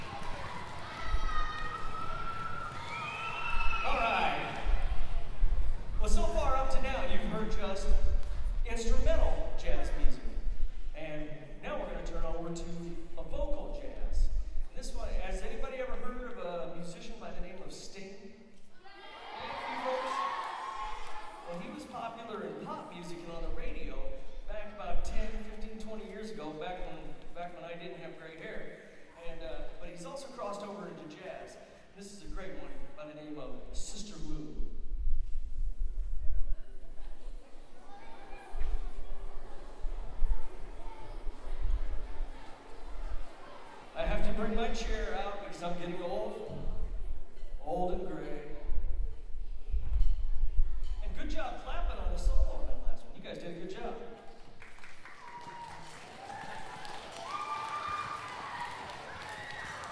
I remember this concert; it was in a grade school gymnasium in Luxembourg and as you can hear, those kids were fantastic.